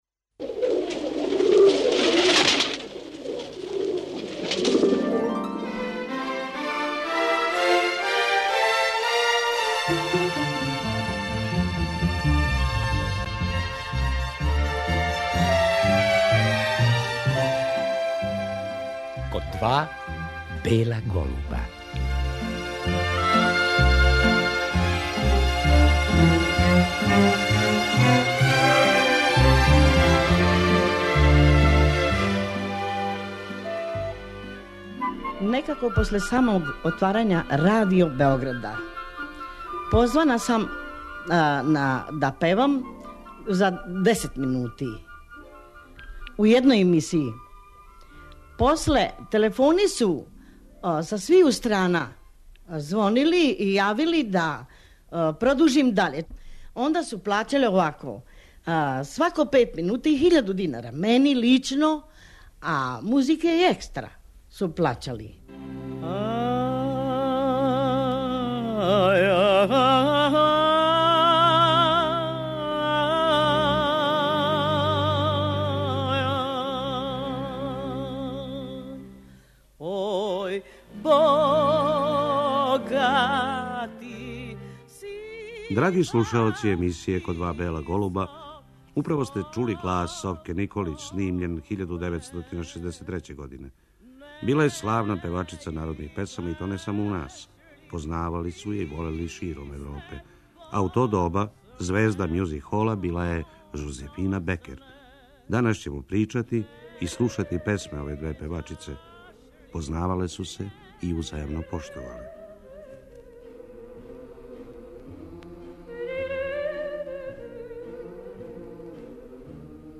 У овој емисији слушаћемо песме тих славних певачица.